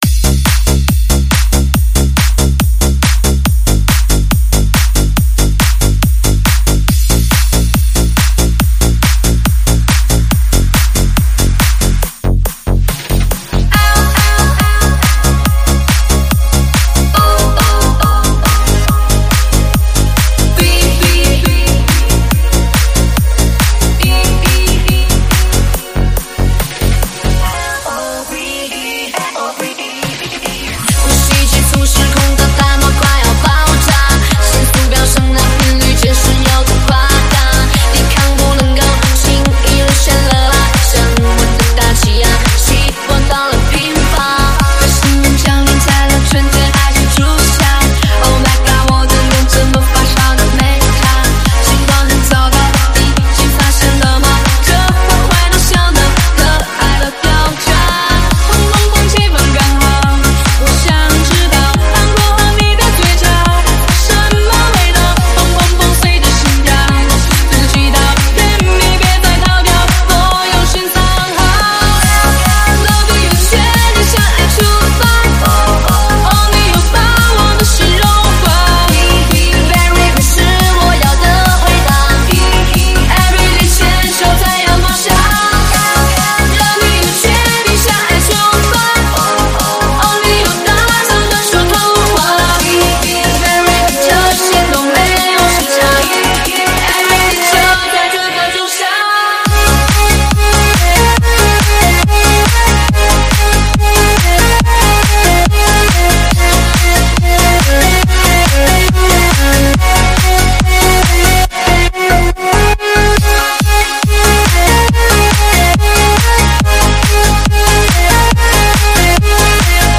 试听文件为低音质，下载后为无水印高音质文件 M币 4 超级会员 M币 2 购买下载 您当前未登录！